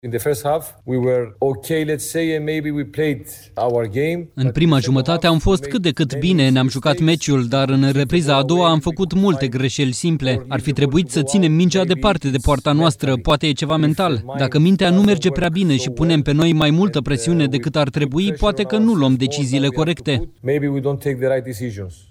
31iul-13-Charalambous-–-Cred-ca-s-au-prabusit-mental-Tradus.mp3